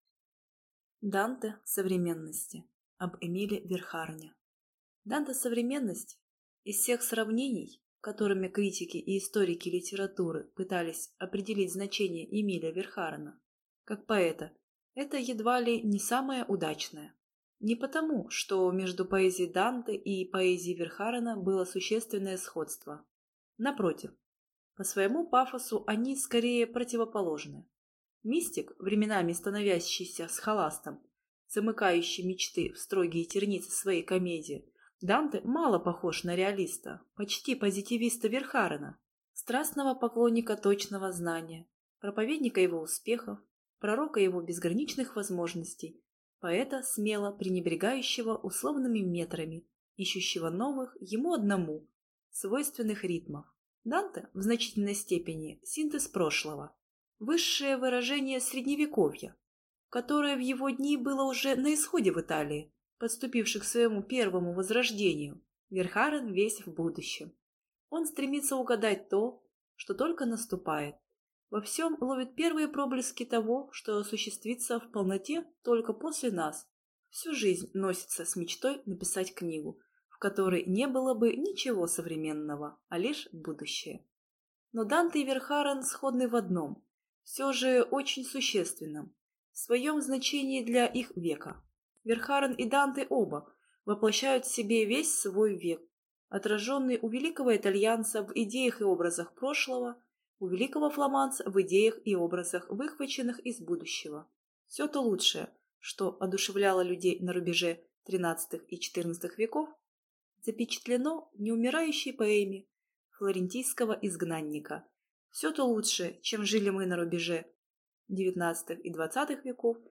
Аудиокнига Данте современности | Библиотека аудиокниг